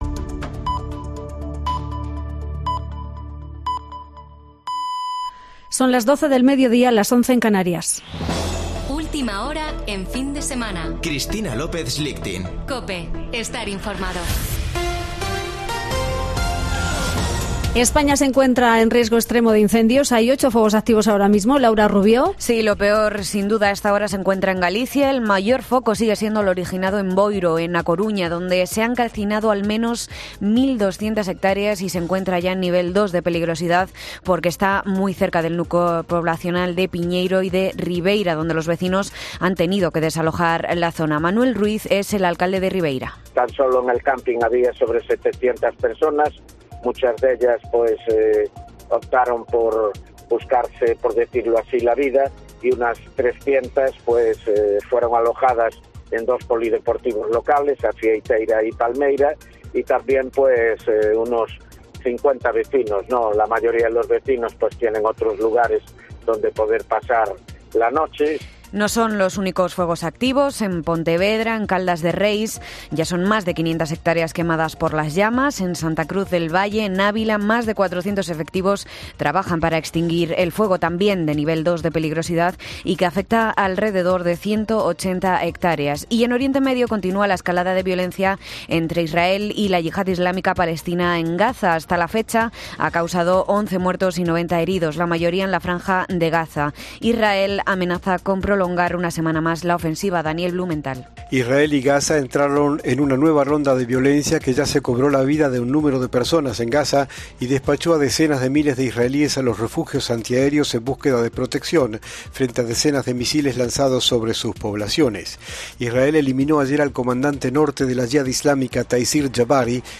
Boletín de noticias de COPE del 6 de agosto de 2022 a las 12.00 horas